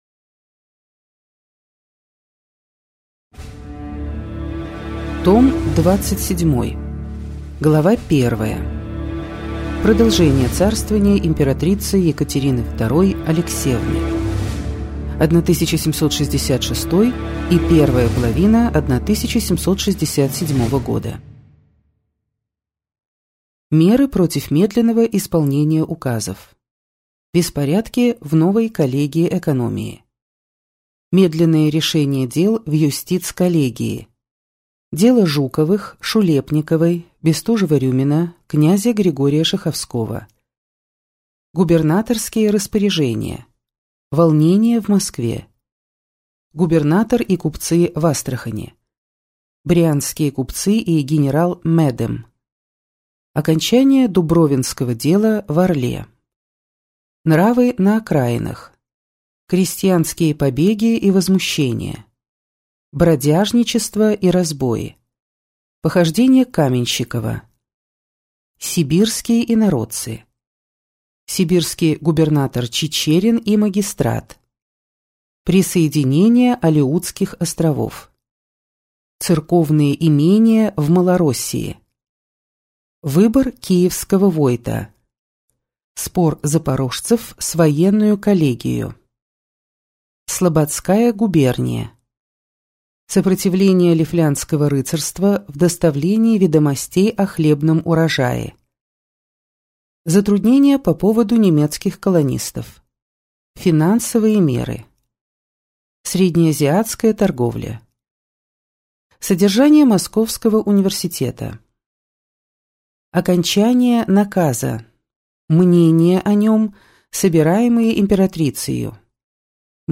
Аудиокнига История России с древнейших времен. Том 27. ЦАРСТВОВАНИЕ ИМПЕРАТРИЦЫ ЕКАТЕРИНЫ II АЛЕКСЕЕВНЫ. 1766–1768 | Библиотека аудиокниг